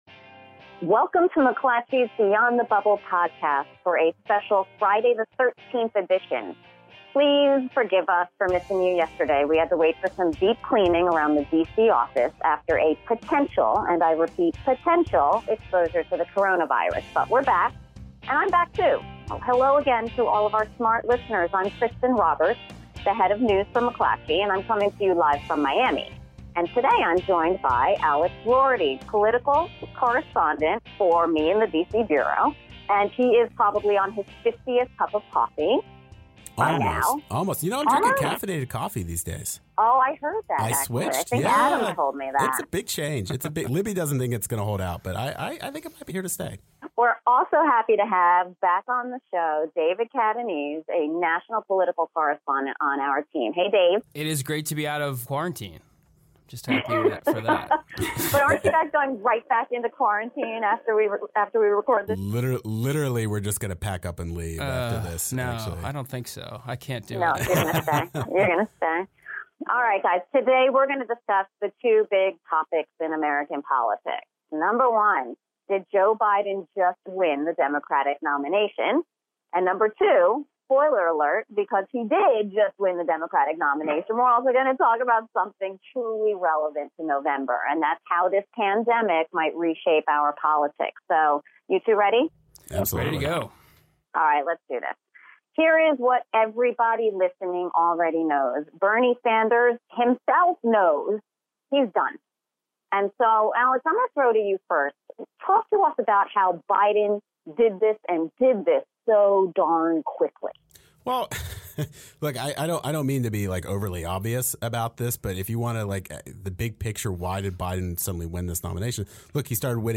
calling in from Miami